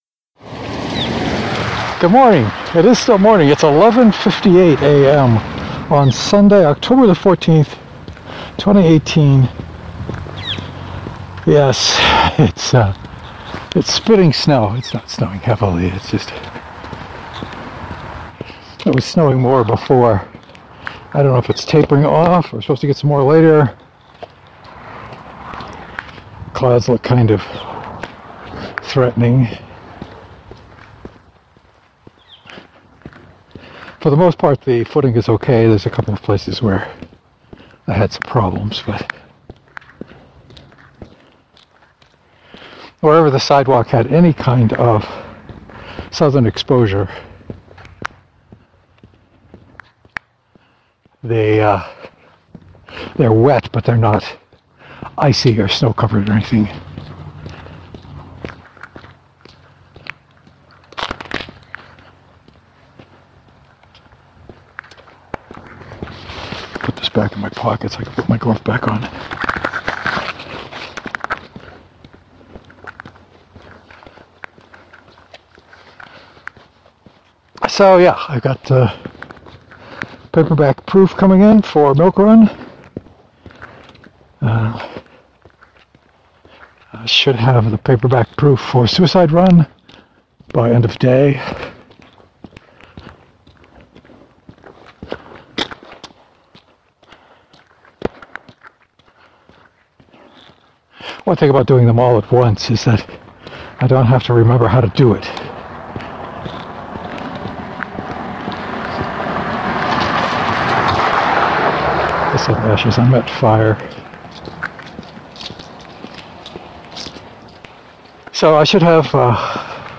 It was still snowing when I set off on the trek.